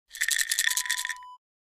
Pill Bottle Shake Sound Effect Wav #1
Description: Pill bottle being shaken. Pills rattle inside.
Properties: 48.000 kHz 16-bit Stereo
A beep sound is embedded in the audio preview file but it is not present in the high resolution downloadable wav file.
pill-bottle-preview-1.mp3